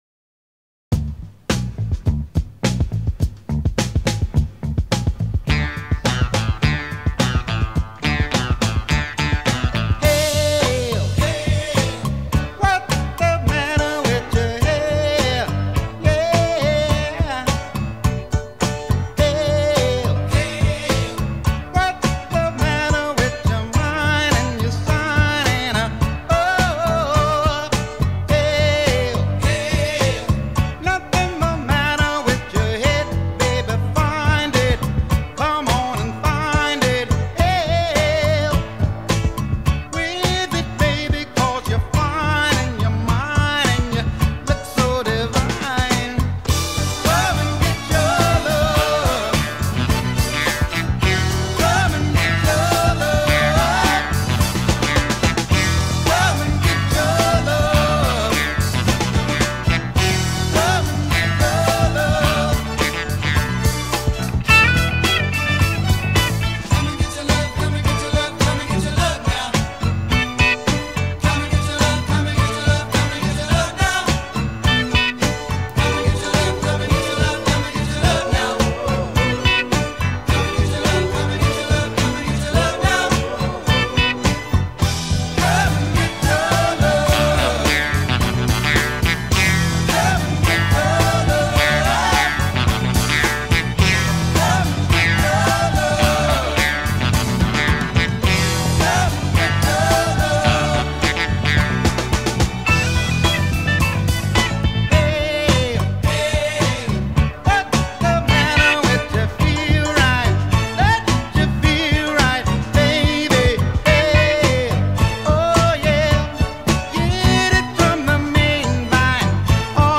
BPM104-111
Audio QualityCut From Video